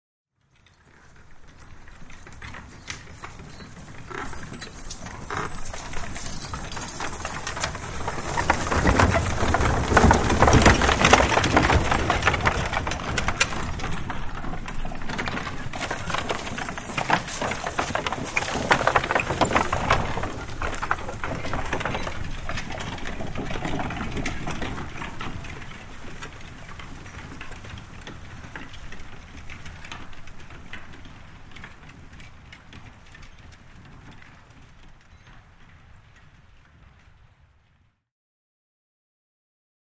2 Horse Drawn Wagons; By Slow, Grass Surface, L-r, With Light Background Wind Through Trees